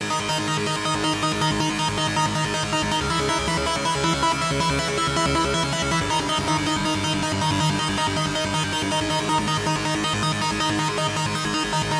Tag: 80 bpm Electro Loops Synth Loops 2.02 MB wav Key : Unknown